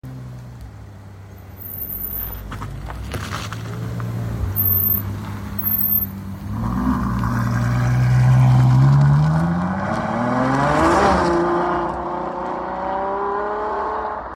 Ferrari lusso brakes traction leaving sound effects free download
Ferrari lusso brakes traction leaving car meet